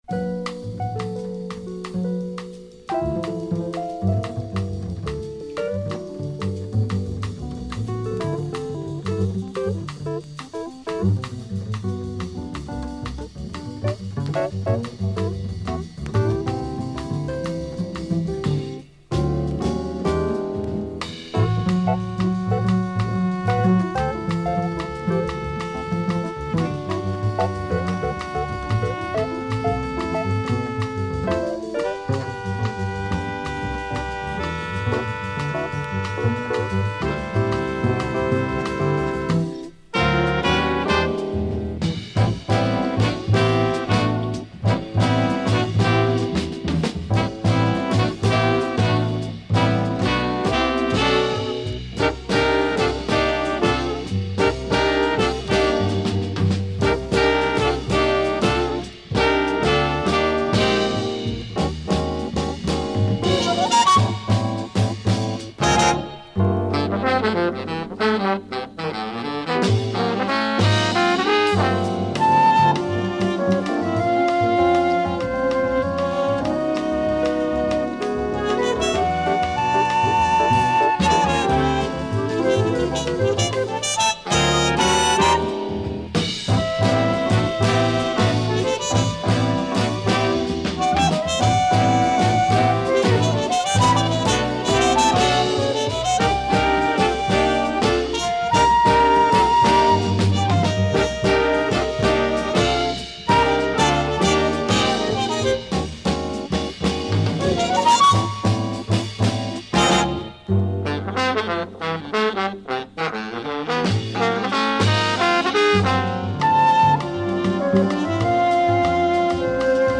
Super rare Italian library
recorded in the mid 70's and released later.
keys
bass clarinet and flute. Serious, deep, beautiful.